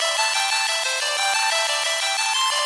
Index of /musicradar/shimmer-and-sparkle-samples/90bpm
SaS_Arp04_90-C.wav